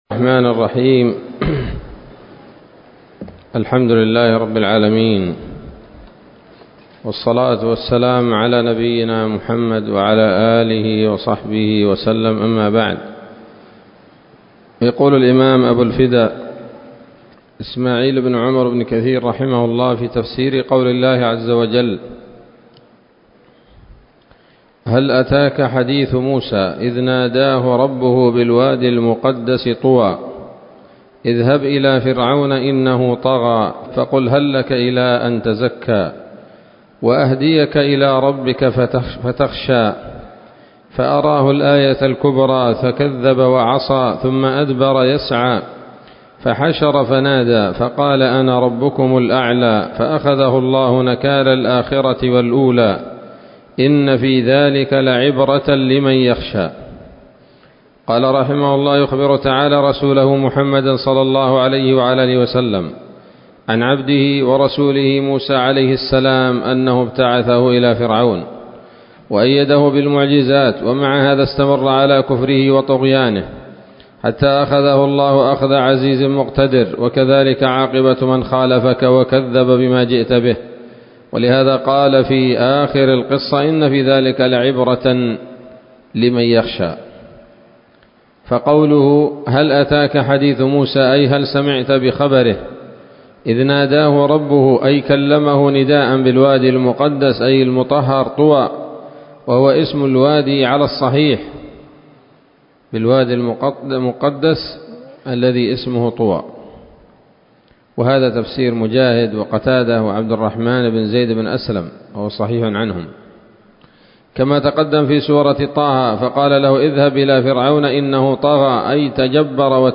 الدرس الثاني من سورة النازعات من تفسير ابن كثير رحمه الله تعالى